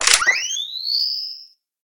gauss_draw.ogg